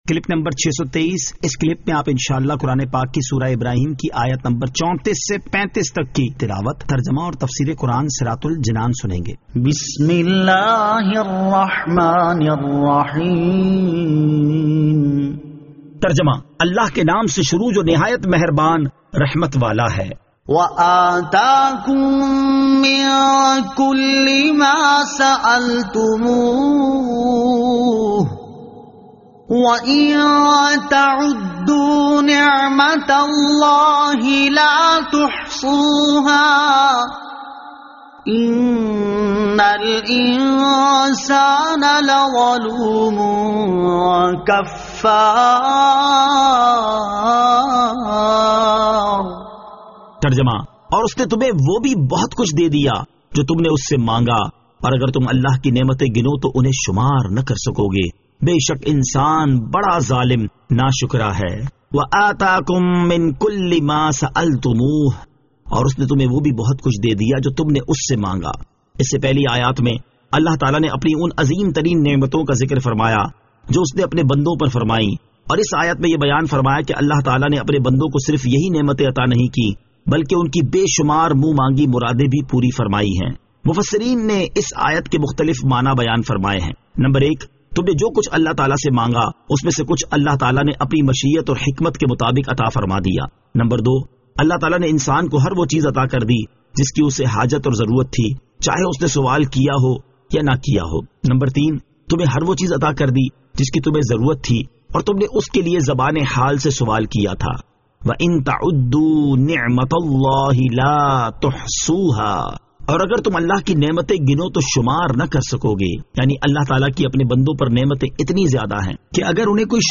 Surah Ibrahim Ayat 34 To 35 Tilawat , Tarjama , Tafseer